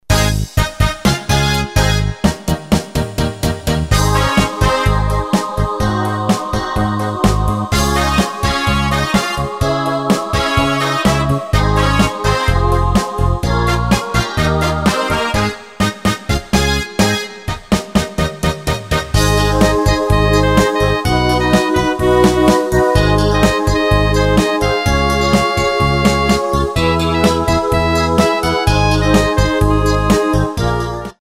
Tempo: 126 BPM.
MP3 with melody DEMO 30s (0.5 MB)zdarma